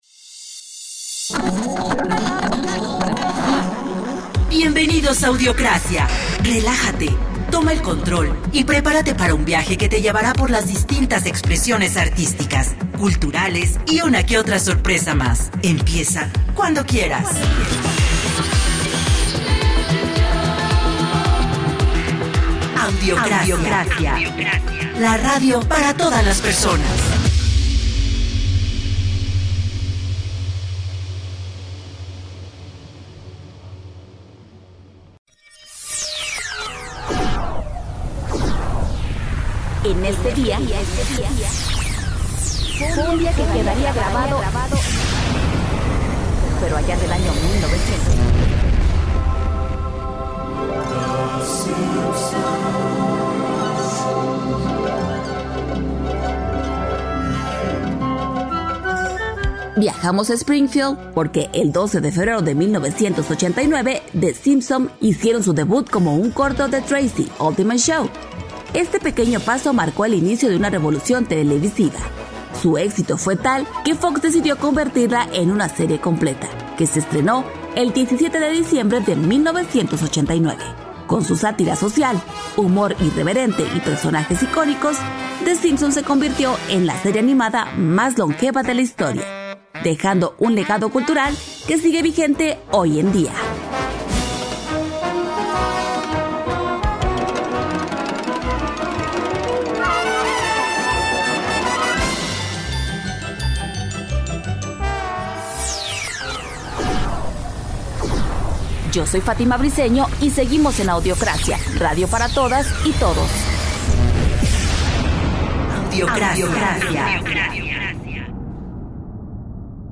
la mejor música e invitados